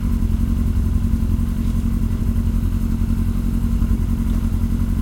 sounds_motorbike_idle.ogg